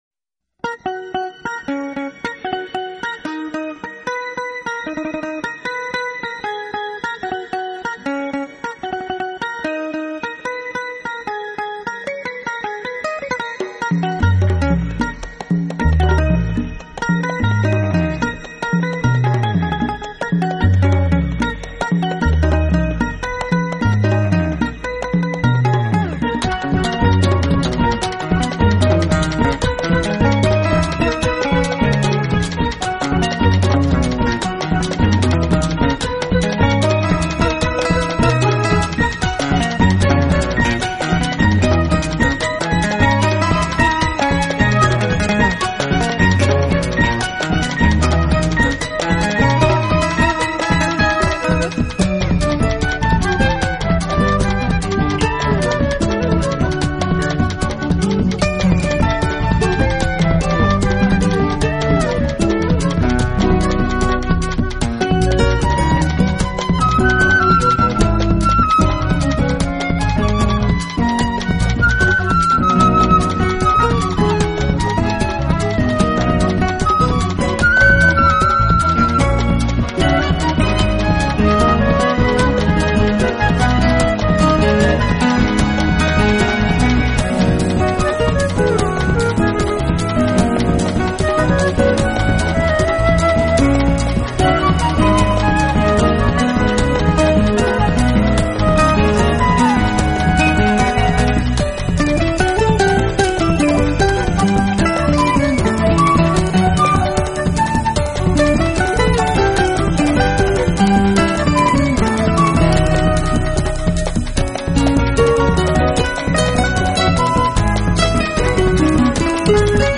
专辑类型：Jazz